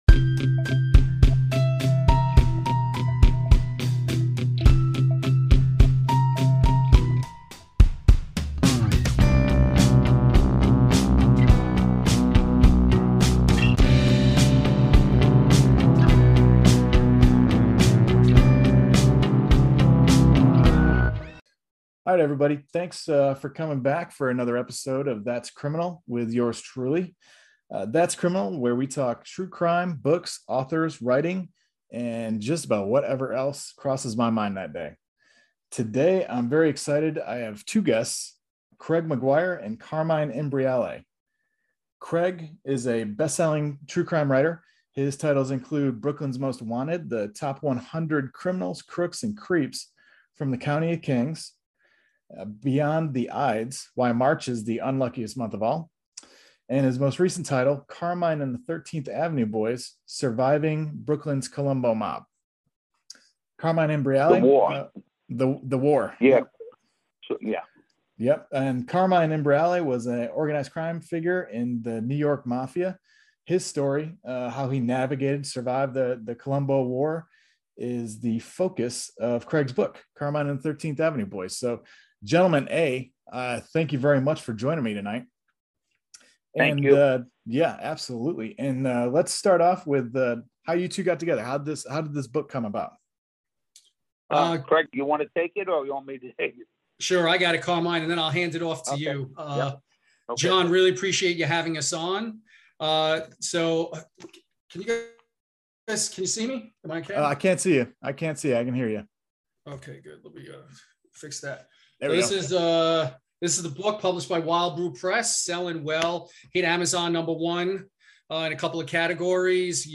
This interview was a riot from start to finish.